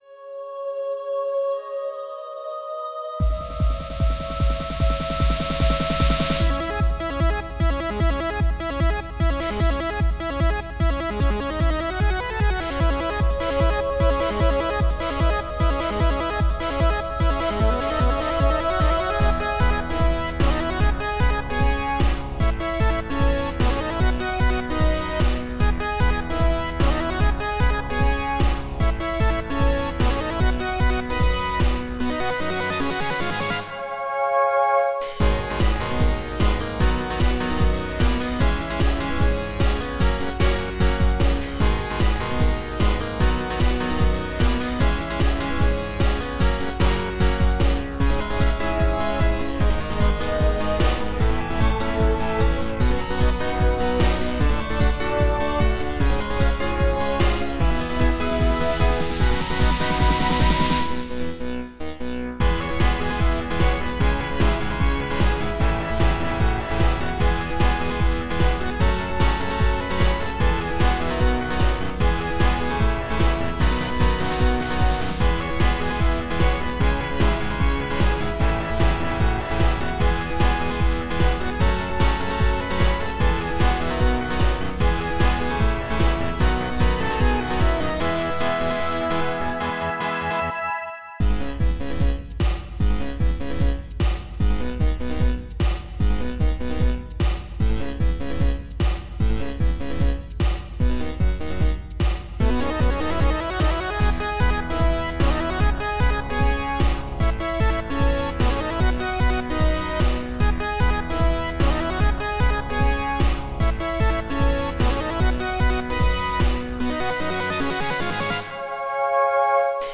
【オフボーカル音源コーラスなし（mp3）】